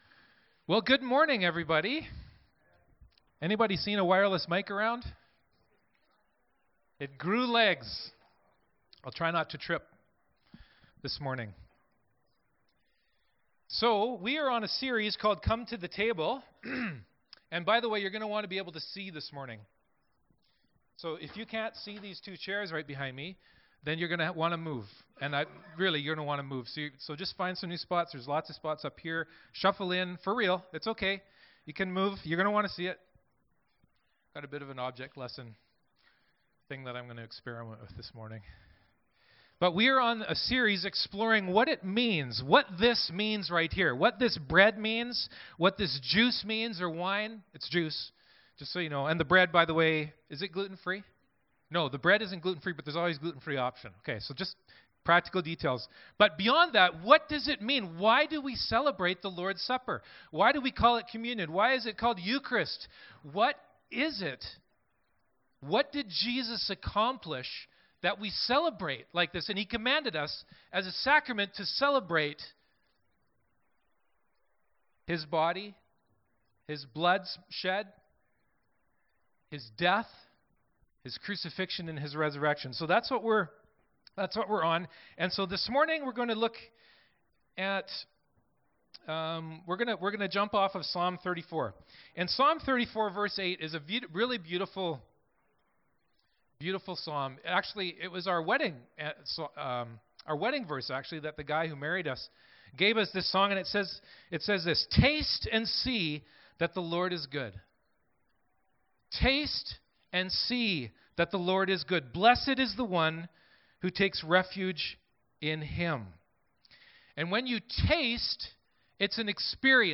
Passage: Psalm 34, Psalm 22, Isaiah 59, Revelation 1 Service Type: Downstairs Gathering